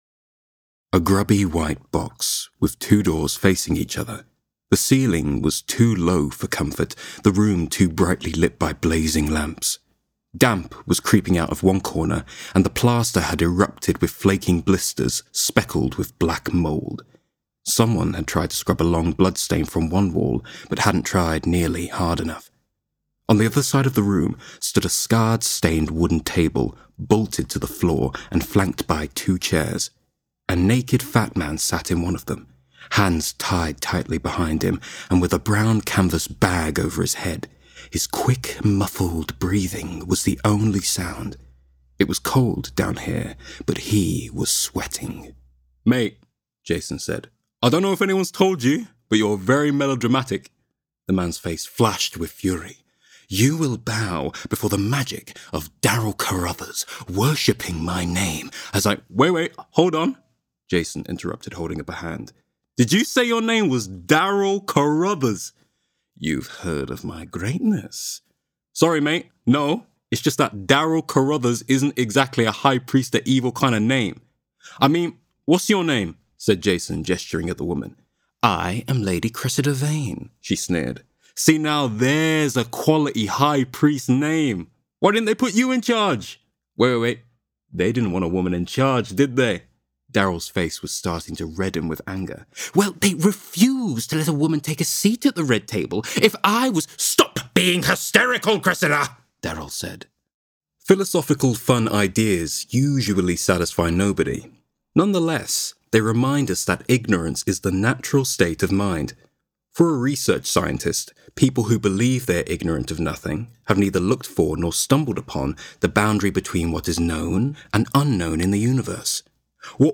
Narration Showreel
Male
West Midlands
Down To Earth